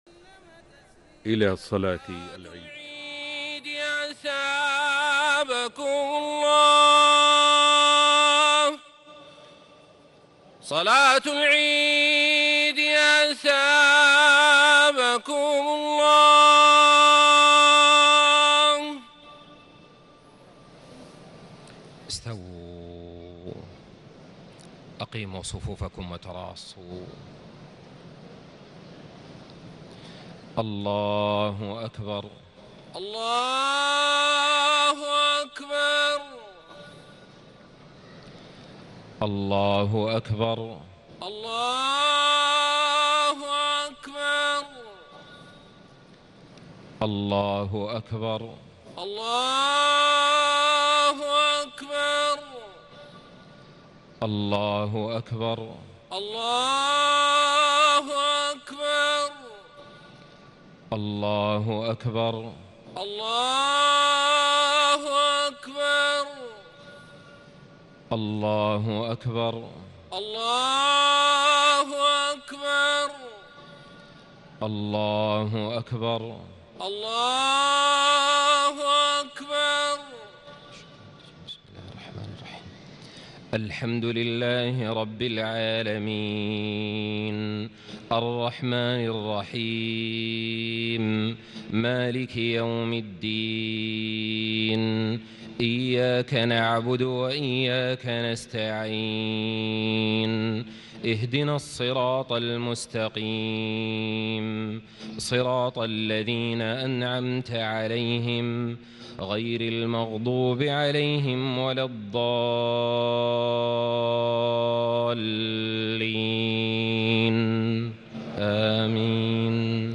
صلاة عيد الأضحى 10 ذو الحجة 1437هـ سورتي الأعلى و الغاشية > 1437 🕋 > الفروض - تلاوات الحرمين